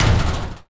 ShotgunImpact.wav